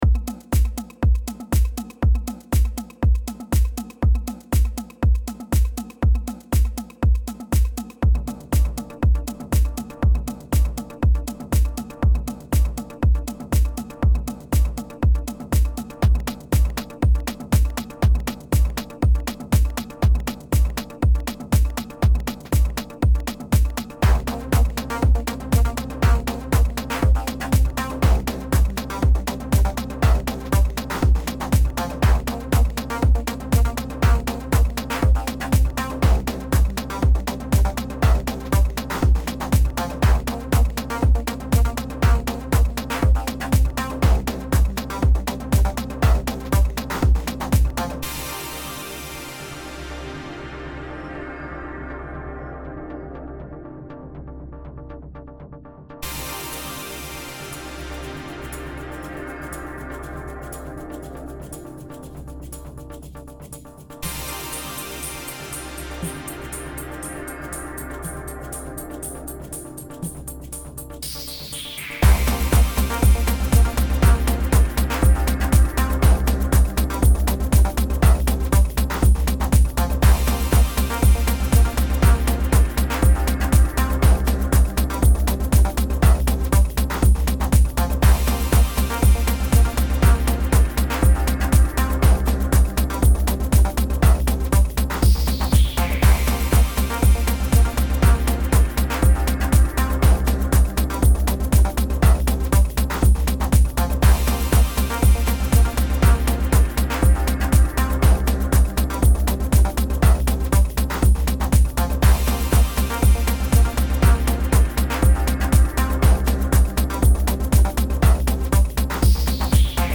Enregistré et mixé à SGEL, Studio 2, Paris, France